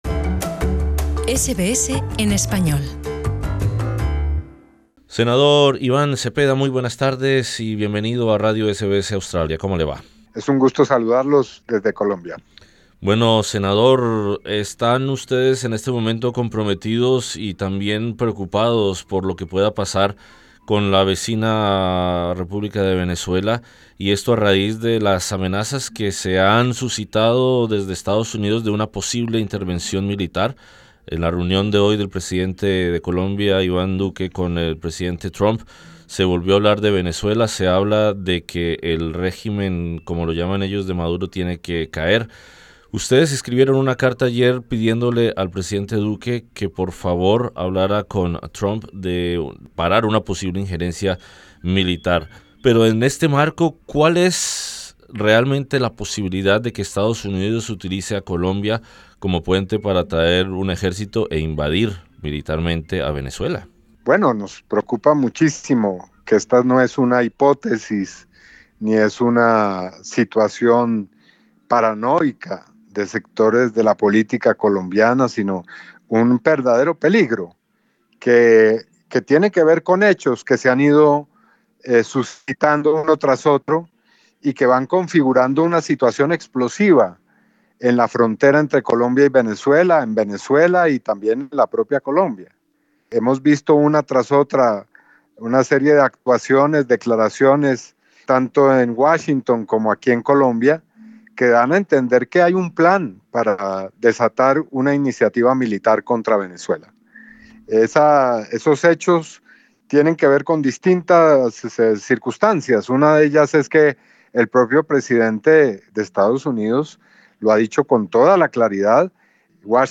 El grupo está liderado por el senador del partido Polo Democrático, Iván Cepeda, y SBS Radio conversó con él.